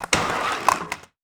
Skateboard Normal Grind.wav